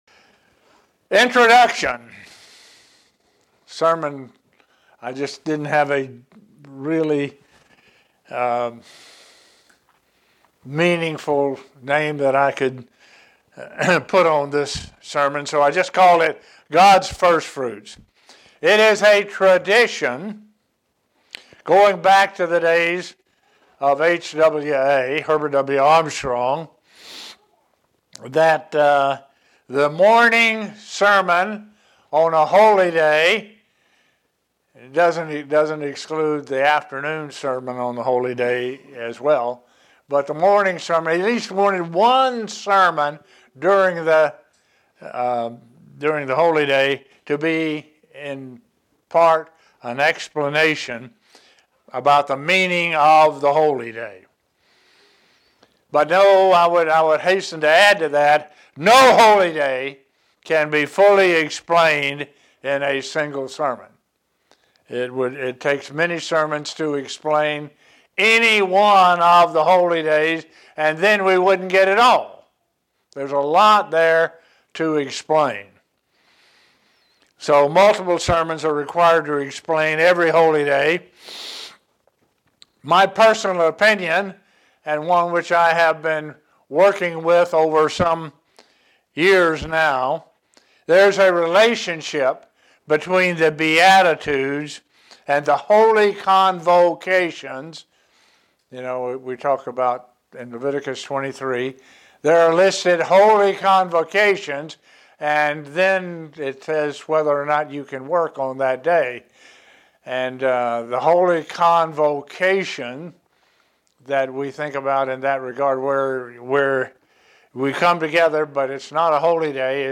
Sermons
Given in Buffalo, NY Elmira, NY